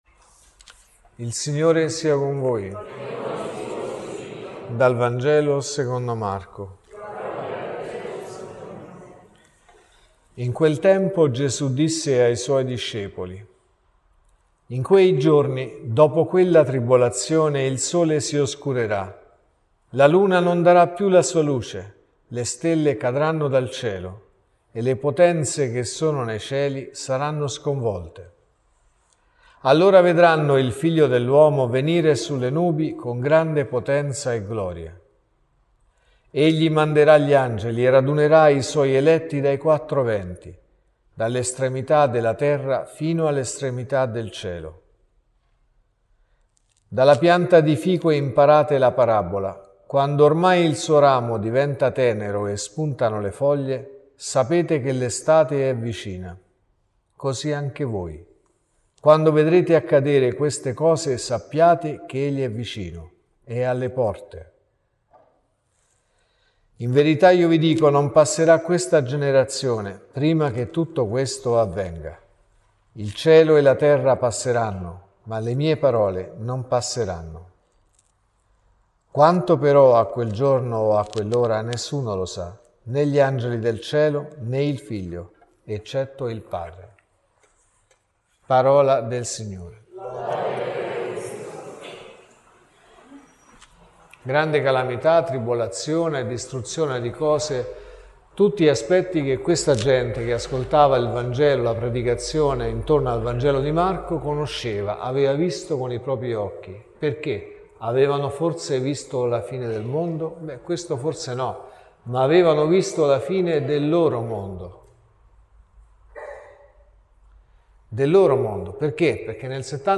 (Messa del mattino e della sera) | Omelie LETTURE: Vangelo, Prima lettura e Seconda lettura Dal Vangelo secondo Marco (Mc 13,24-32) .